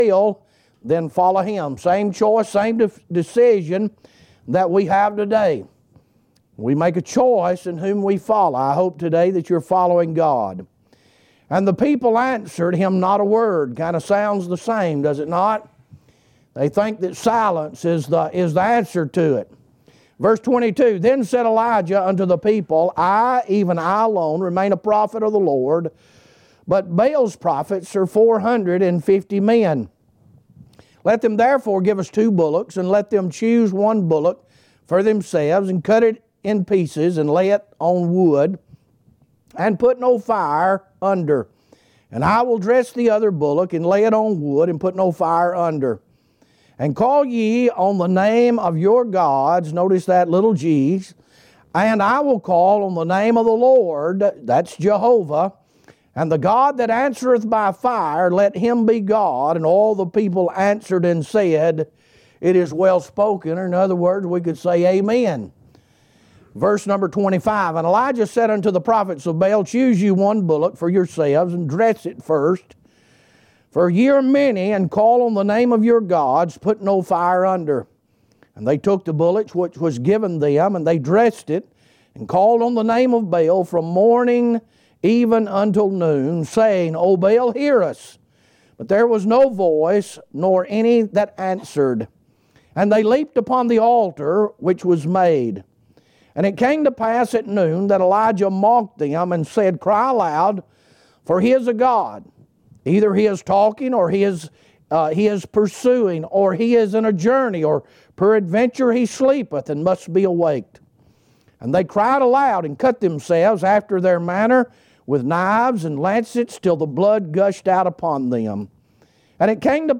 Evening Sermon